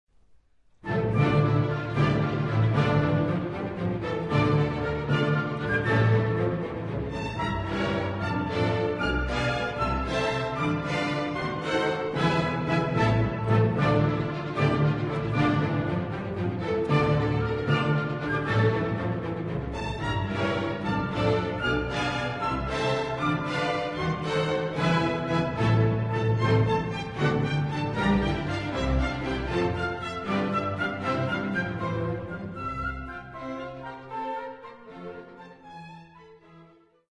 Symphony No. 4 in D minor Op. 120